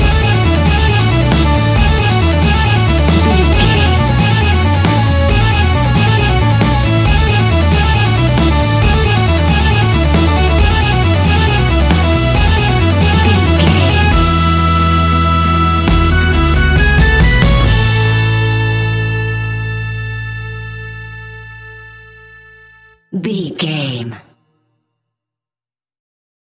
Ionian/Major
acoustic guitar
mandolin
double bass
accordion